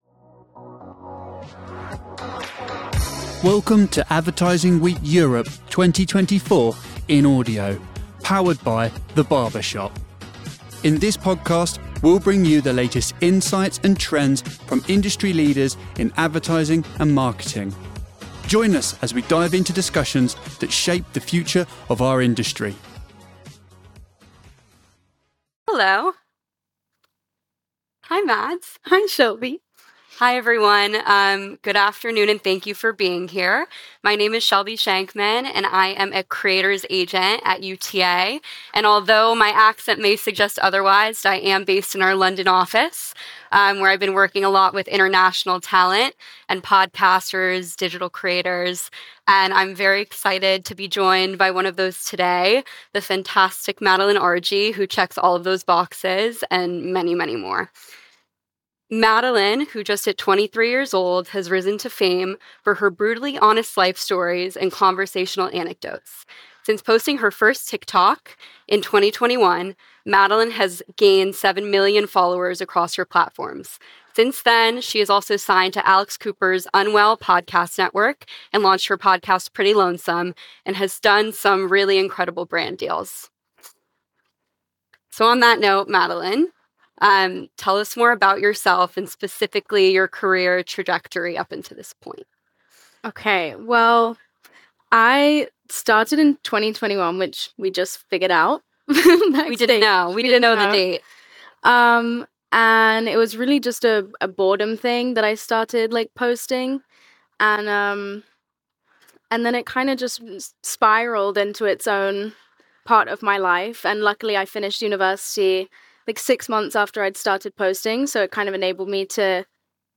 Explore why authenticity and openness are the currency in marketing, especially when it comes to connecting with Gen Z audiences and how entertainment is becoming increasingly important to feel a sense of connectivity. This session offers valuable insights into building genuine connections and creating content that resonates with younger audiences.